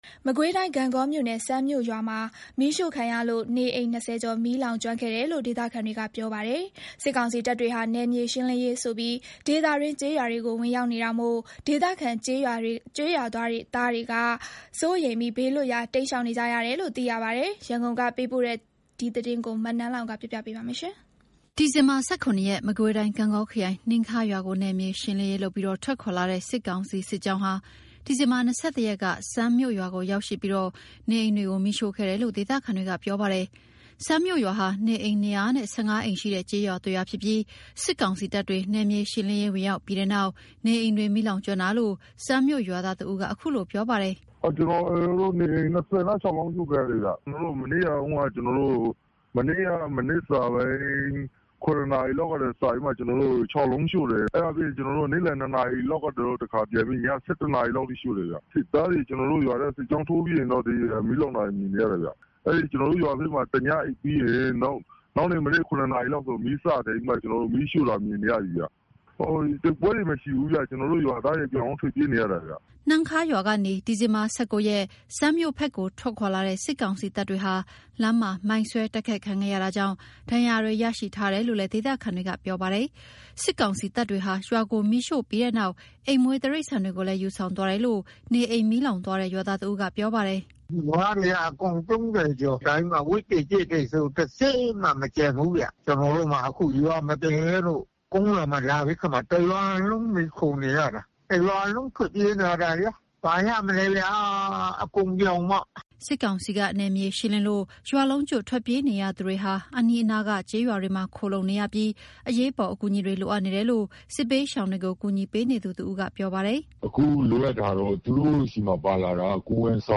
(ဒေသခံရွာသား)